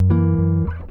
gtr_05.wav